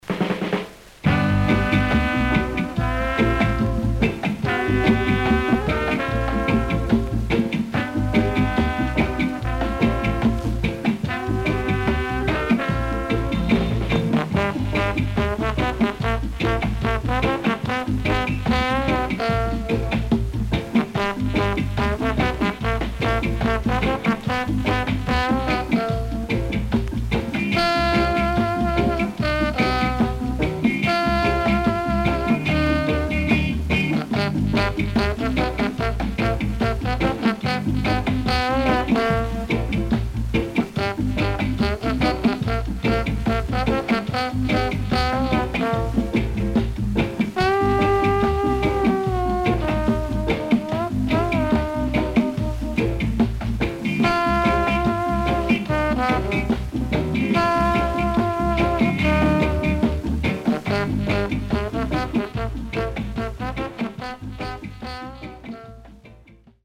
EARLY REGGAE
SIDE A:プレス起因によるヒスノイズ入ります。少しチリノイズ入ります。